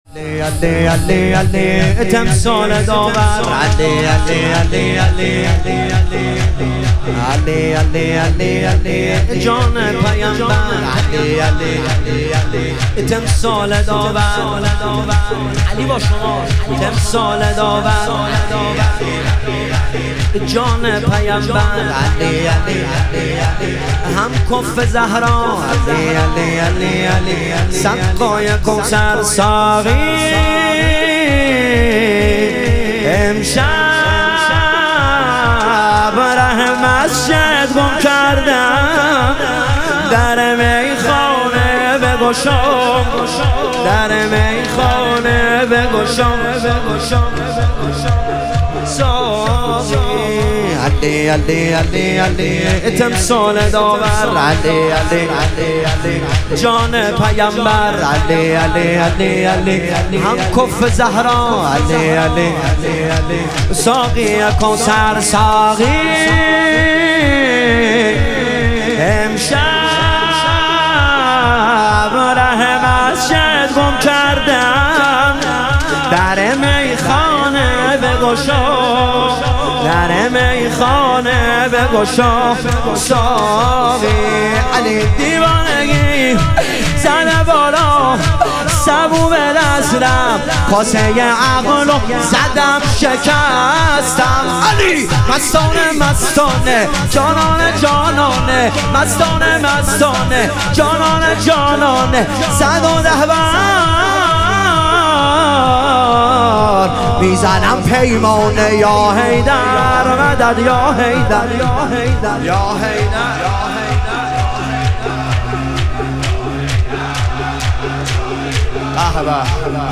شهادت امام هادی علیه السلام - شور